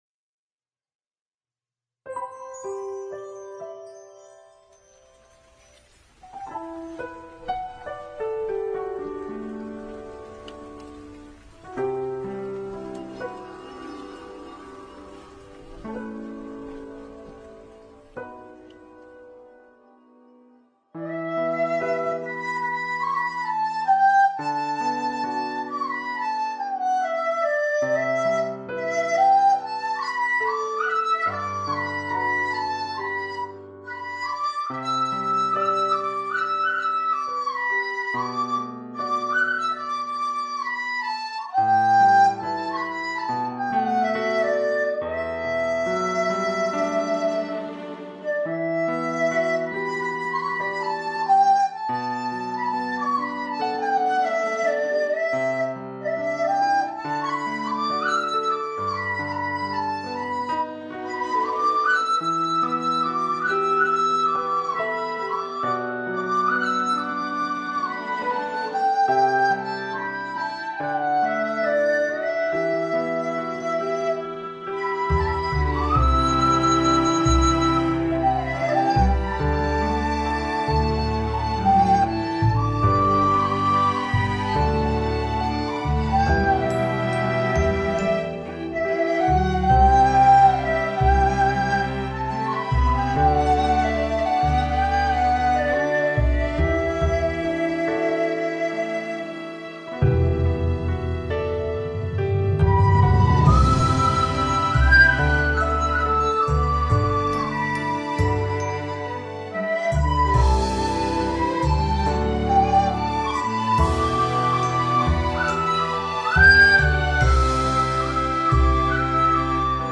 》優美的笛聲，優美的鋼琴伴奏！鼓掌喝彩！
笛聲悠揚很有江南韻味！